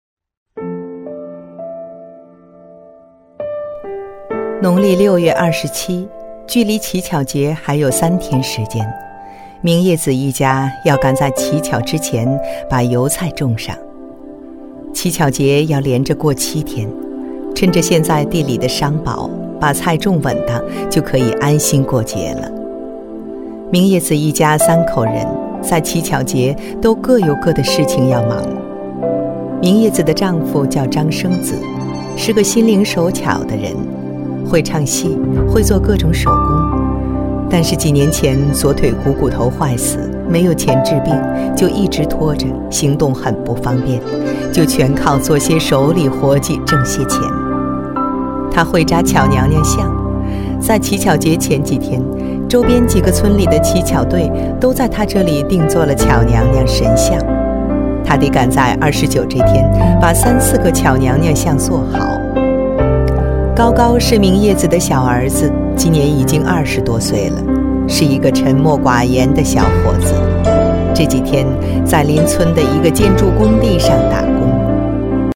配音风格： 自然